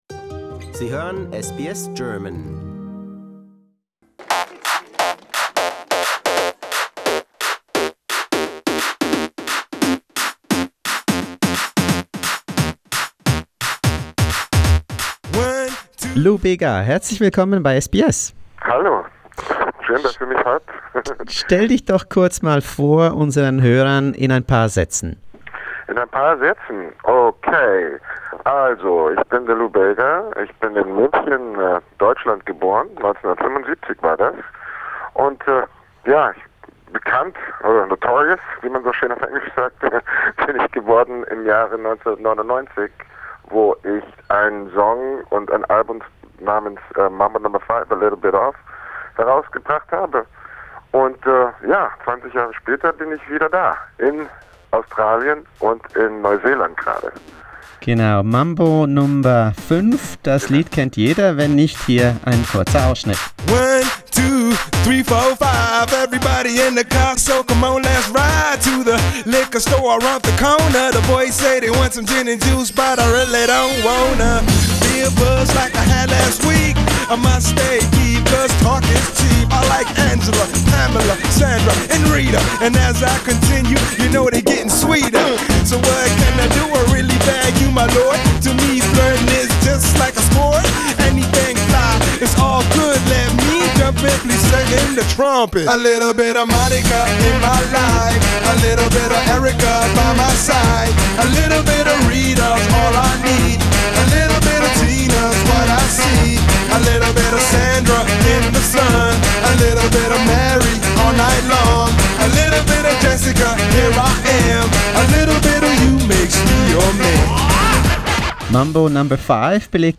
Today: An interview with the King of Mambo, Lou Bega. The German pop sensation spoke to us during his maiden (!) tour in Australia.